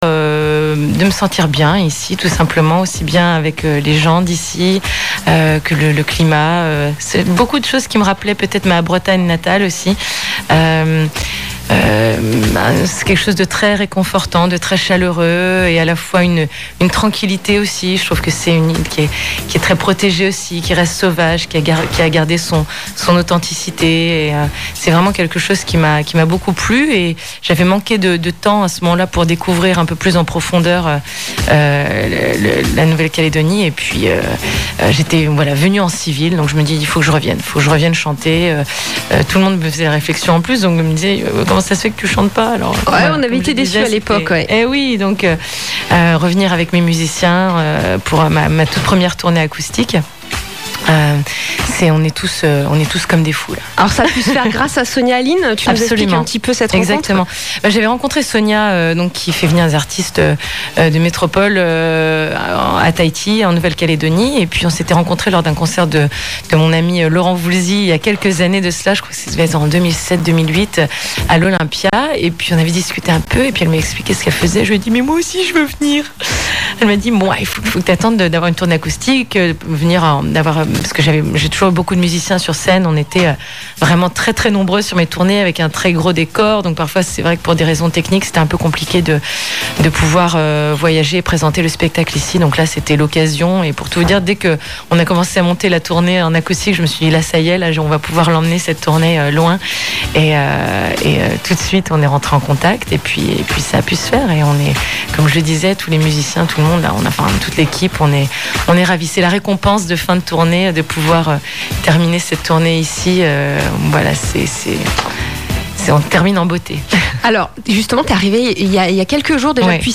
nrj_nouvelle_caledonie-happy_time-interview.mp3